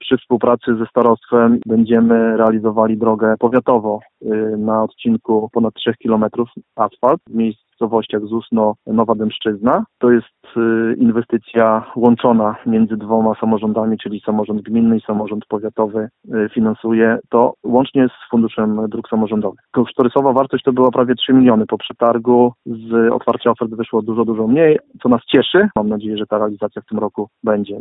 O szczegółach mówi Radiu 5 Tomasz Rogowski, wójt gminy.